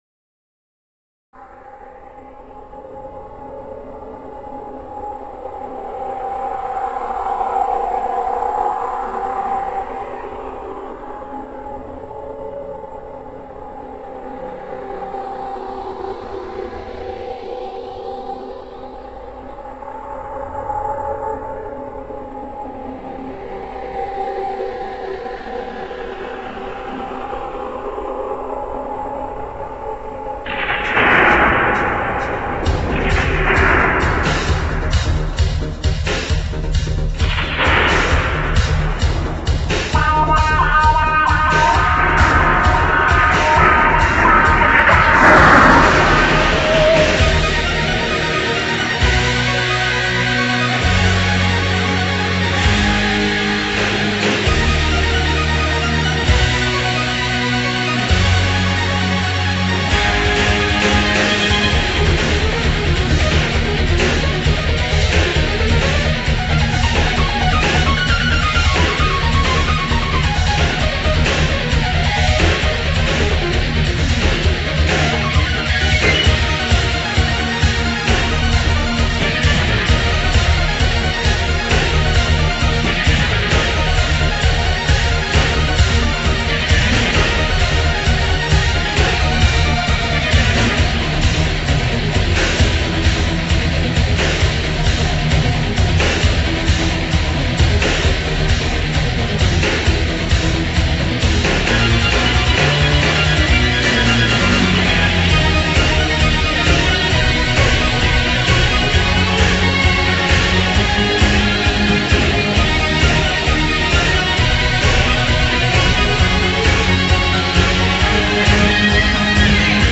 インスト３曲入りシングル
Industrial Heavy Action Roleplaying Game Music